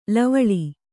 ♪ lavaḷi